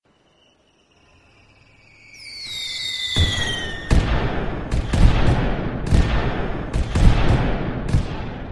Fireworks Sound Effect Free Download
Fireworks